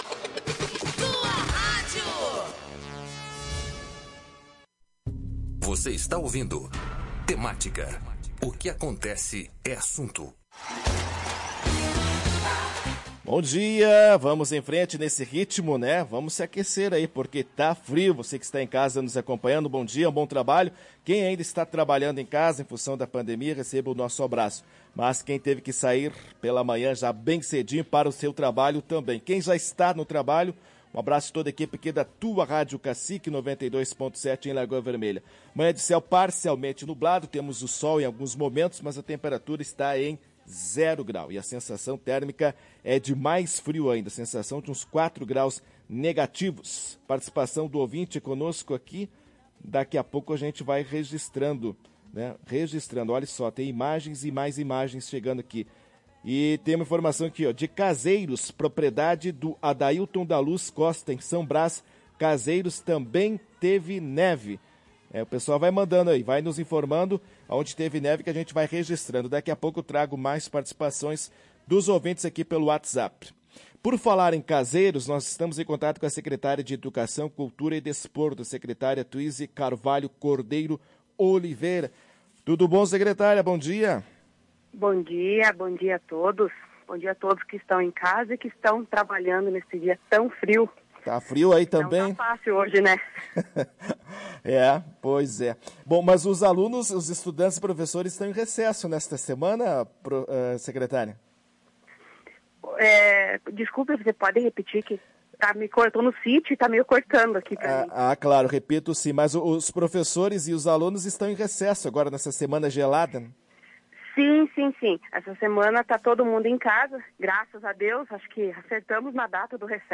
Em entrevista à Tua Rádio Cacique, a secretária de Educação de Caseiros, Tuisi Carvalho Cordeiro Oliveira falou sobre as contratações.